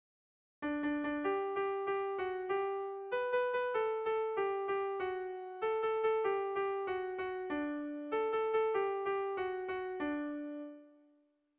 Sehaskakoa
ABD